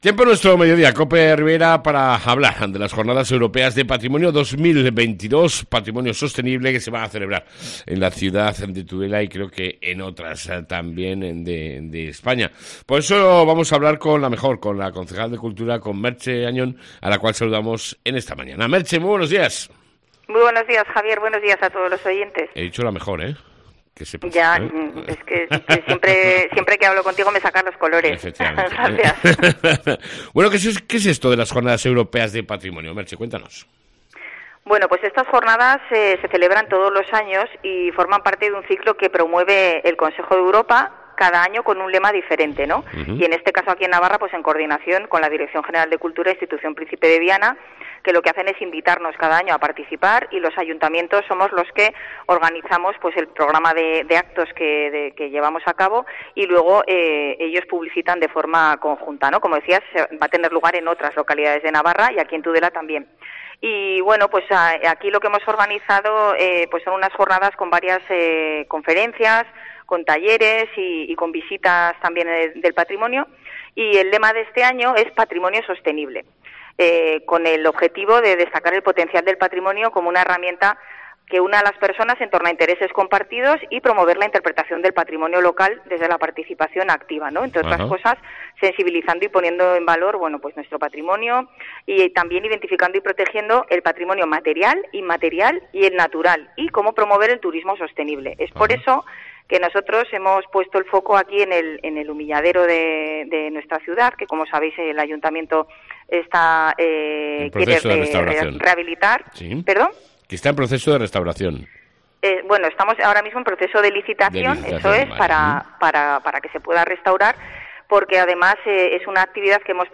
ENTREVISTA CON LA CONCEJAL DE CULTURA MERCHE AÑON